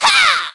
nita_atk_02.ogg